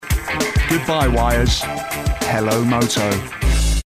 Young, Bright, Punchy with a Cool London Edge. 25-40
Commercial, Cool, Upbeat, Smooth
London